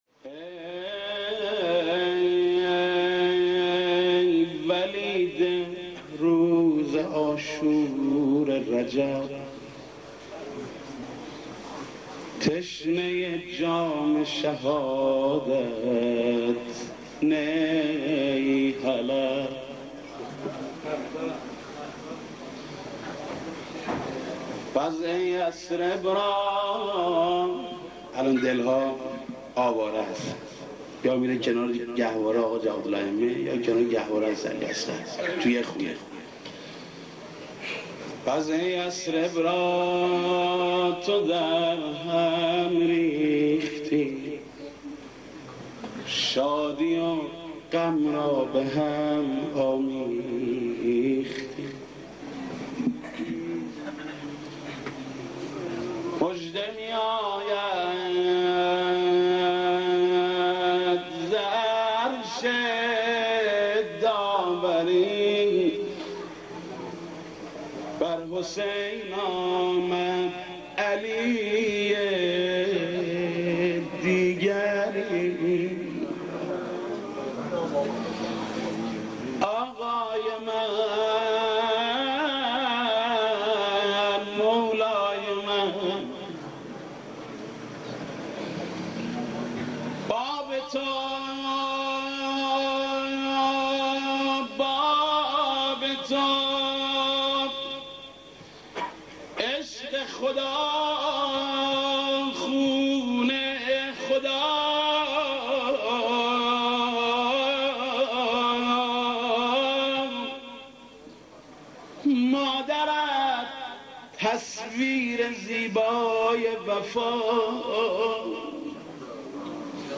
مولودی به مناسبت میلاد امام‌ جواد(ع)